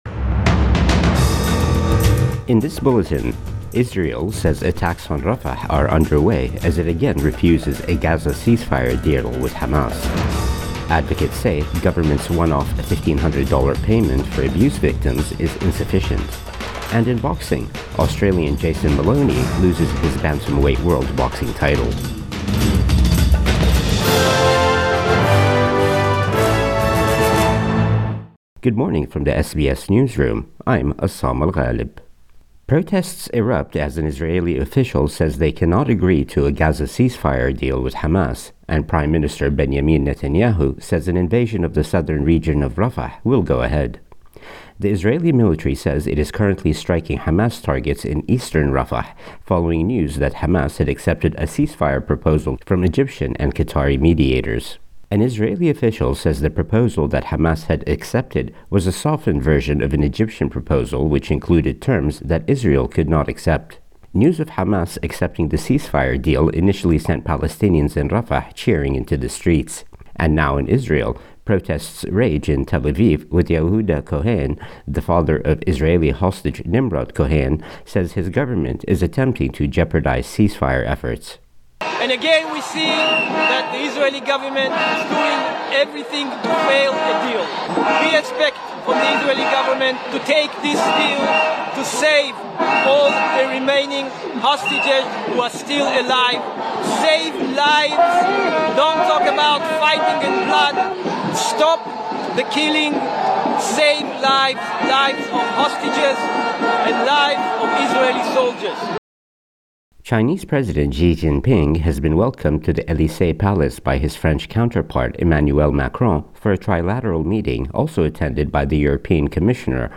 Morning News Bulletin 7 May 2024